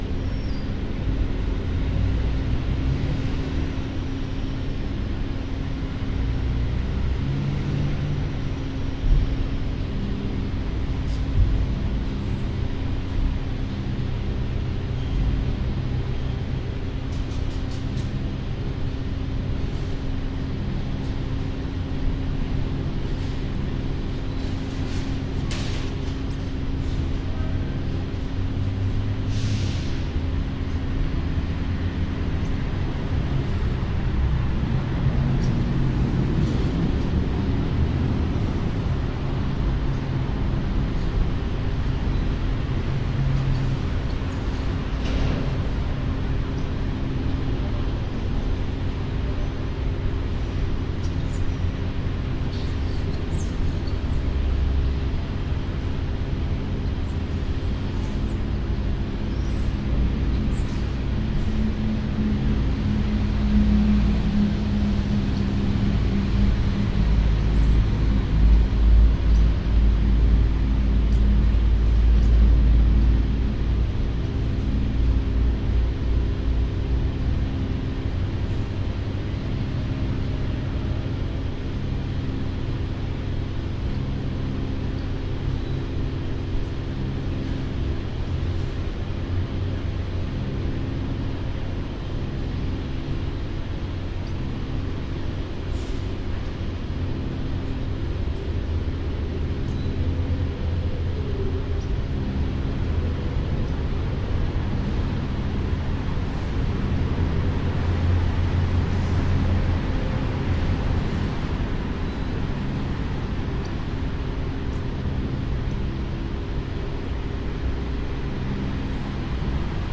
glitch lub 90bpm " mud
描述：环境突发事件循环
标签： 环境 毛刺
声道立体声